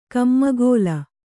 ♪ kammagōla